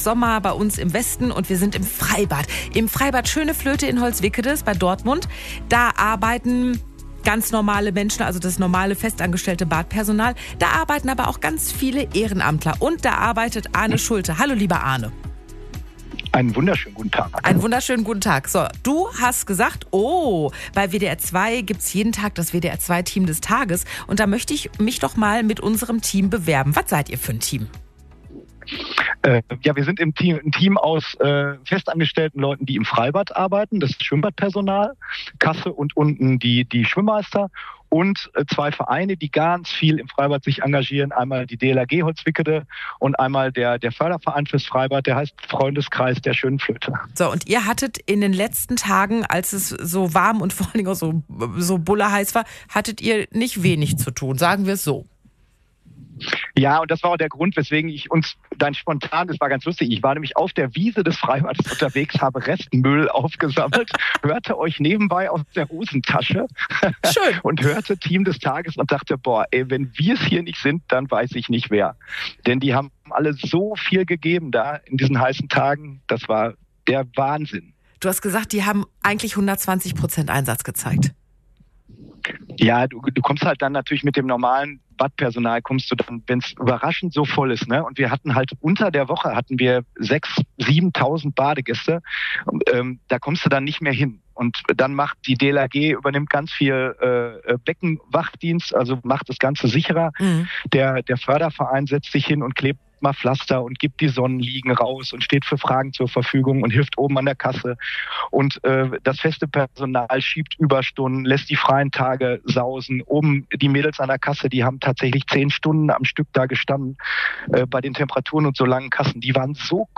Am vergangenen Freitag wurde unser komplettes Freibadteam bestehend aus den festangestellten Badpersonal, der DLRG Holzwickede und dem Förderverein bei der Aktion „Team des Tages“ bei WDR 2 vorgestellt und wir könnten nicht stolzer sein.
Ihr seid der Grund, warum wir im Radio waren.